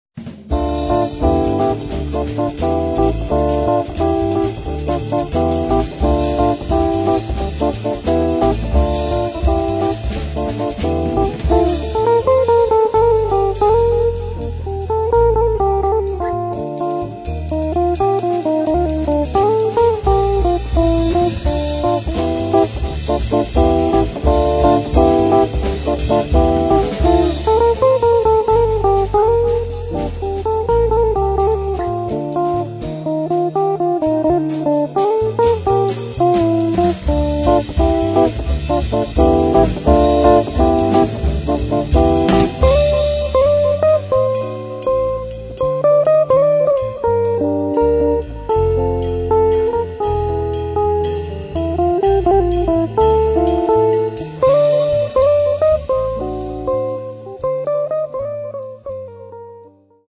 Registrato e missato presso il Midi Groove Studio (Napoli)
Chitarre
Basso elettrico
Batteria